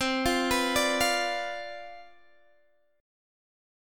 Listen to CM7sus2sus4 strummed